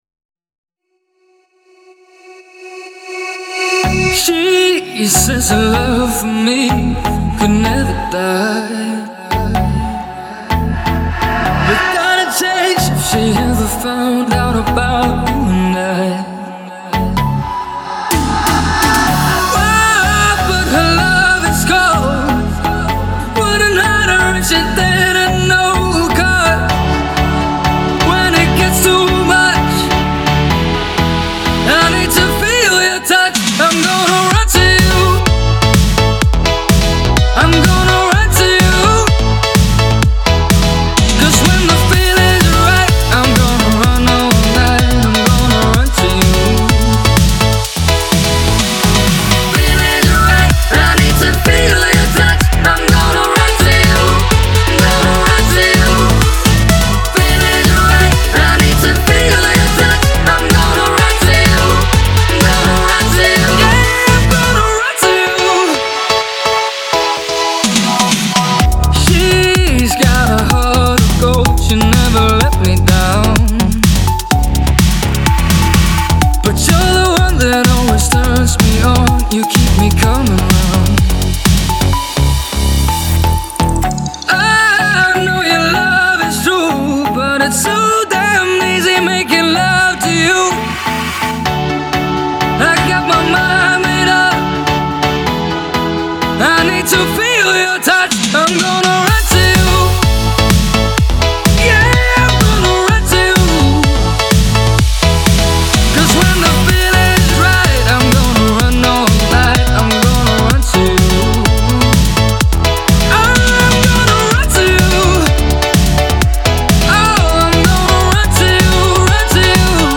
это энергичная трек в жанре электронный поп